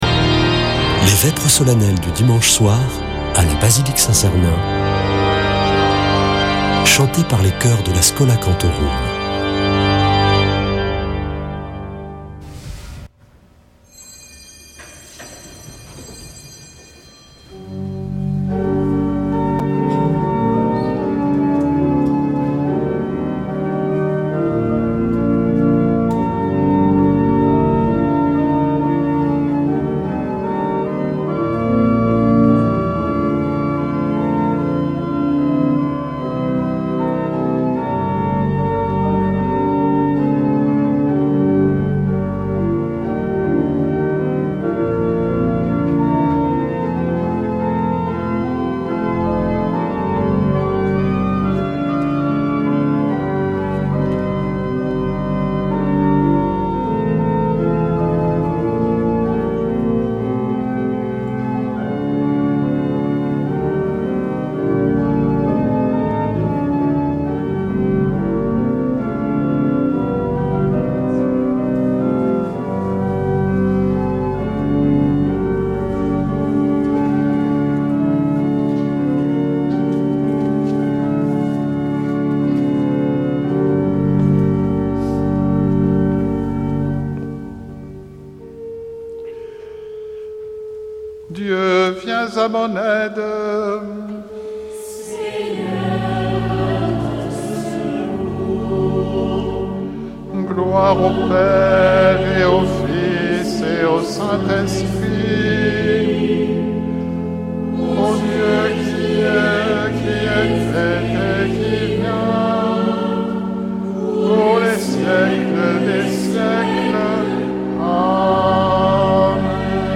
Vêpres de Saint Sernin du 25 janv.